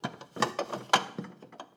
Babushka / audio / sfx / Kitchen / SFX_Plates_02.wav
SFX_Plates_02.wav